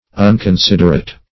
Search Result for " unconsiderate" : The Collaborative International Dictionary of English v.0.48: Unconsiderate \Un`con*sid"er*ate\, a. Inconsiderate; heedless; careless.